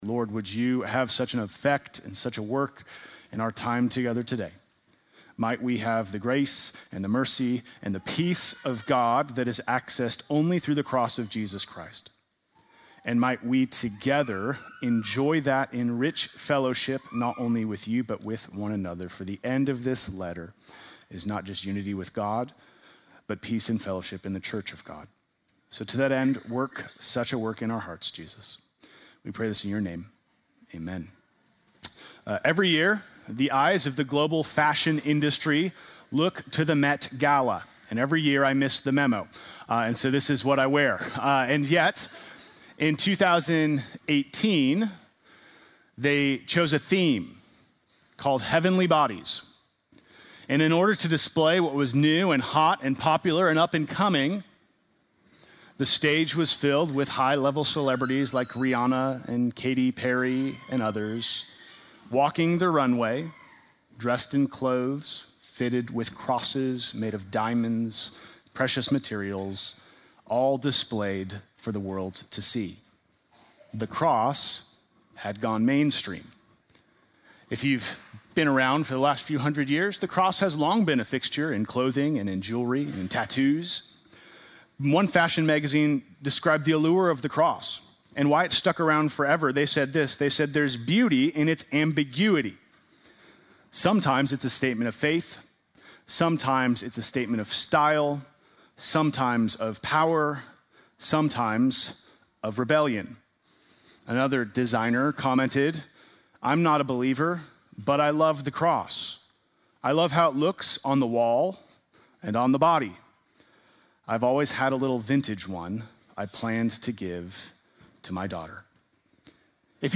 Sunday morning message January 18
sermon